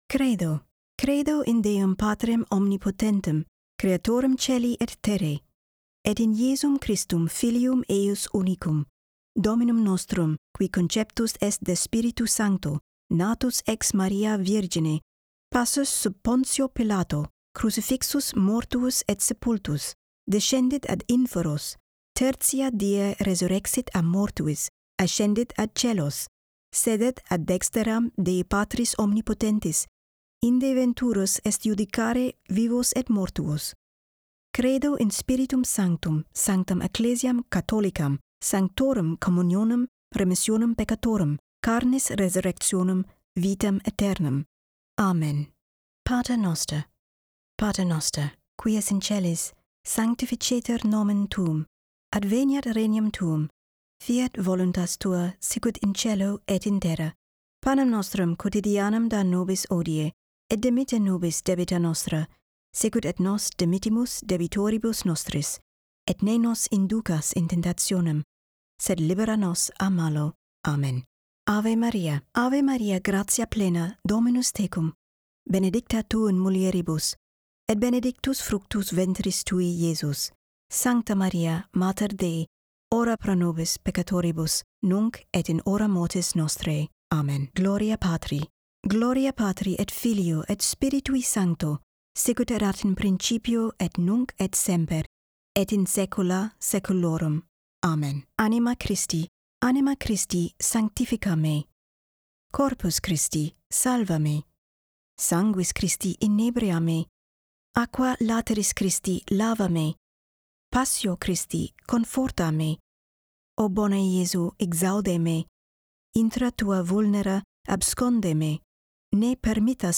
CLASSIC Latin Prayers 1) Credo; 2) Pater Noster (0:45) 3) Ave Maria (1:11); 4) Gloria (1:27); 5) Anima Christi (1:39); 6) Memorare (2:16); 7) Salve Regina (2:46)
I have a warm, clear voice and am capable of expressing a broad range of tones and energies so that I can deliver performances spanning the soothing and mellow to the dynamic and authoritative.
I record in an accoustically treated home studio environment to minimize echoes, noise and reverberation.
To complement the treated studio setting, I use premium microphones: Neumann TLM  103 and Sennheiser MKH 416.